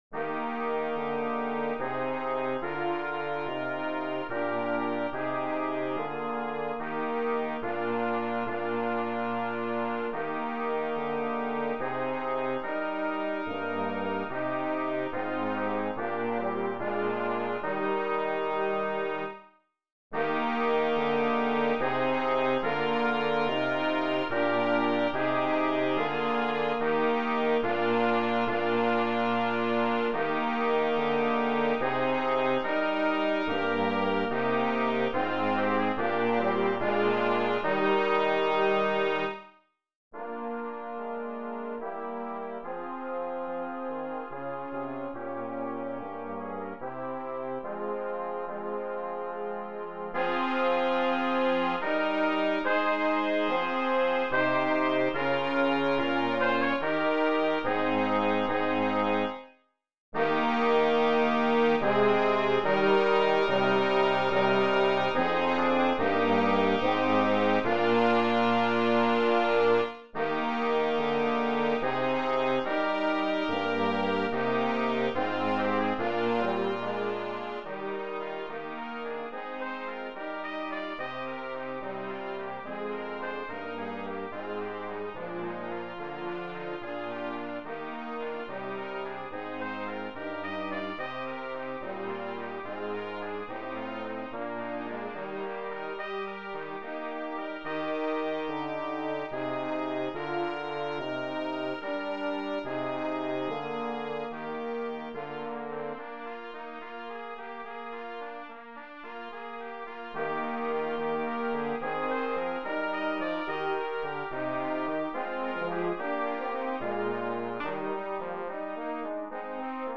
Voicing: Brass Trio